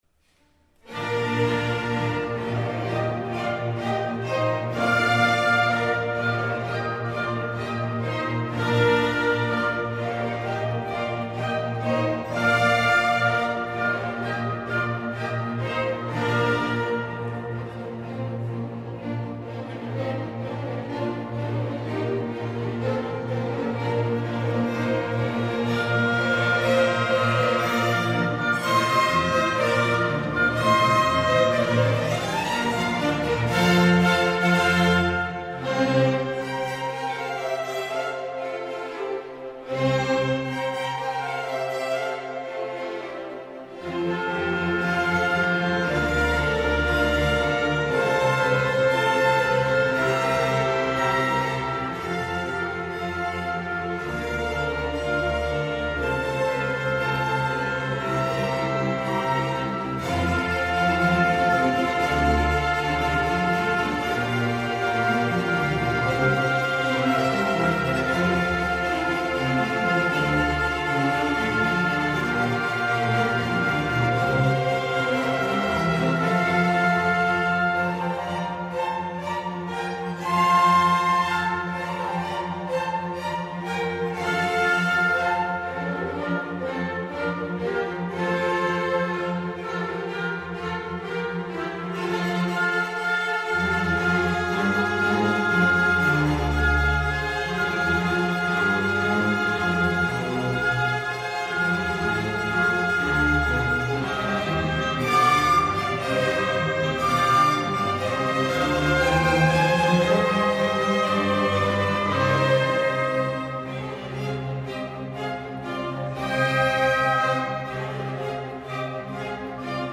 Chor, Solisten und Orchester
in der Entenfußhalle des Klosters Maulbronn
Orchester: